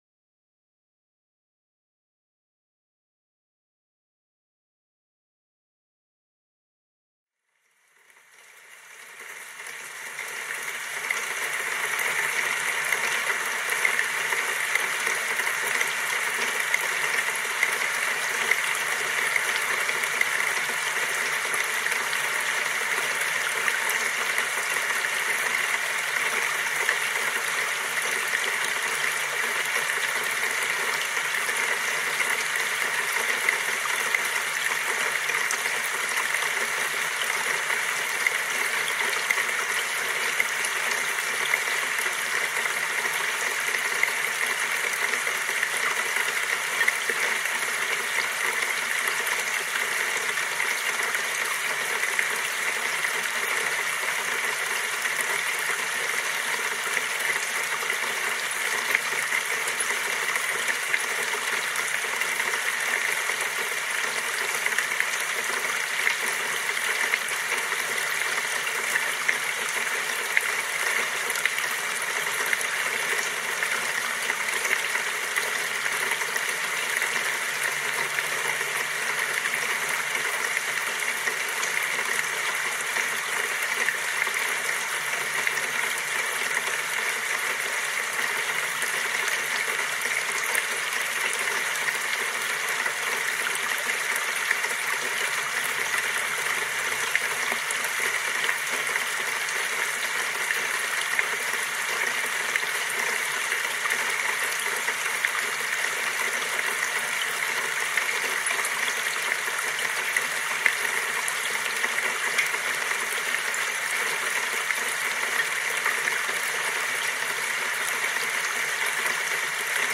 【勉強集中】ブラウンノイズ×ポモドーロタイマー 2時間｜作業向け
私たちが届けるのは、ホワイトノイズ、Brown Noise、そしてAmbient Noiseの間を彷徨う音の彫刻。
時には水音、時には遠雷、時には耳の中の宇宙のようなノイズ。